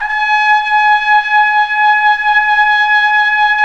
Index of /90_sSampleCDs/Roland LCDP12 Solo Brass/BRS_Tpt _ menu/BRS_Tp _ menu